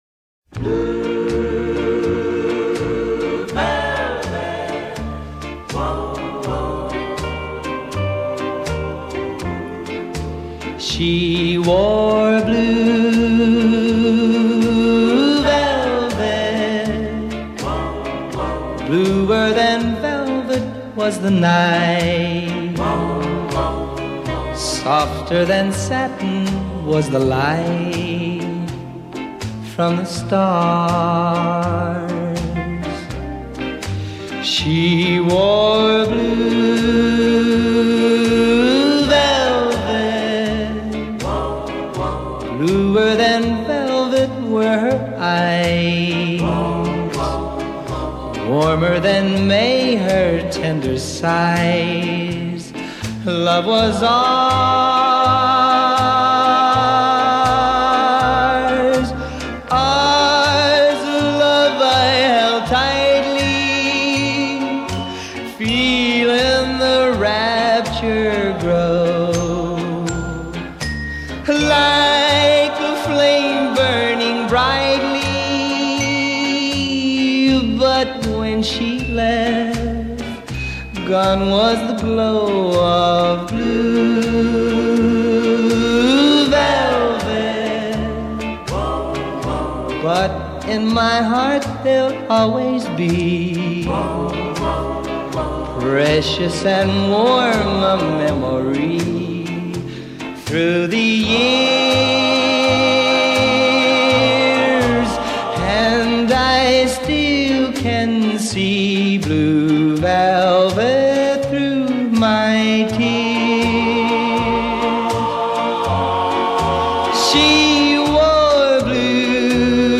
Doo-wop